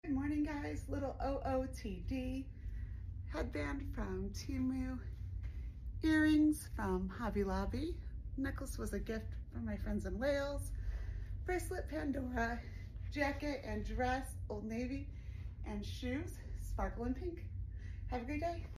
The first days back to school are tough on our vocals!